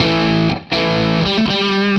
AM_HeroGuitar_120-A02.wav